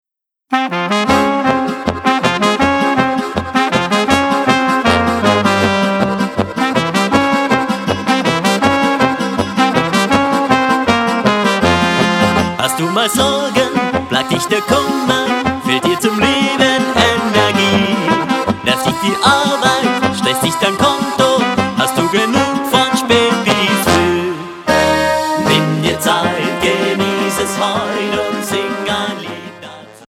Polkalied